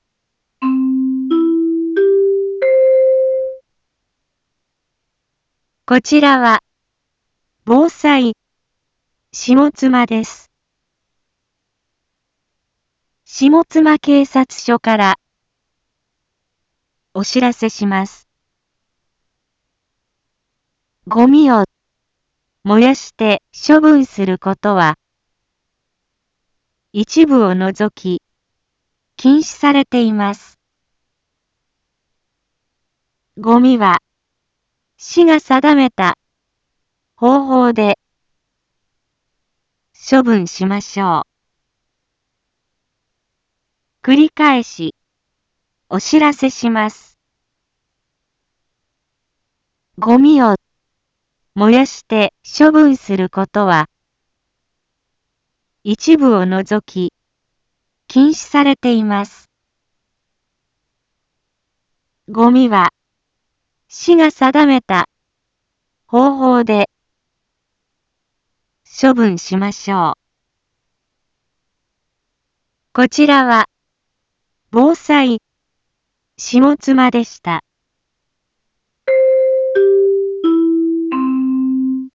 一般放送情報
Back Home 一般放送情報 音声放送 再生 一般放送情報 登録日時：2021-06-25 10:01:26 タイトル：ごみの不法焼却の注意喚起について インフォメーション：こちらは、防災下妻です。